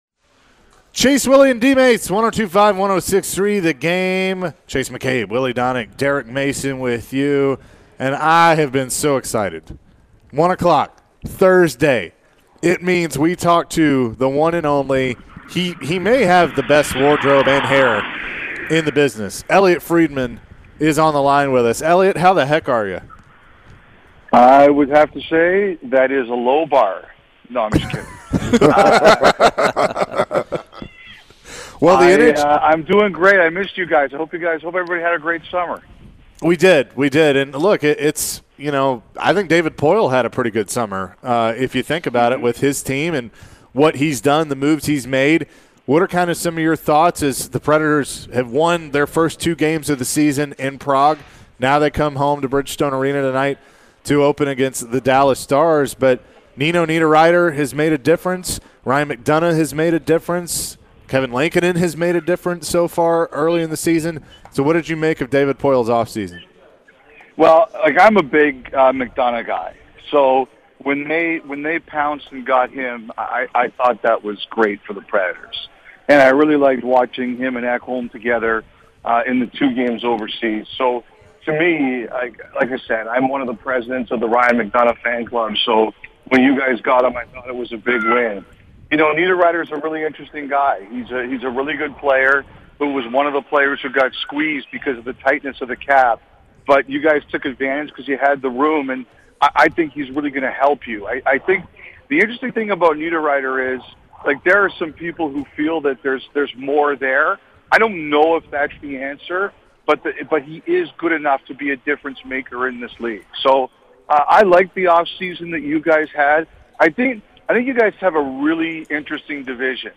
Elliotte Friedman Full Interview (10-13-22)